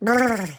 voice_recover.wav